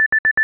Techmino/media/effect/chiptune/warning.ogg at 3226c0c831ec9babe3db1c1e9db3e9edbe00a764
warning.ogg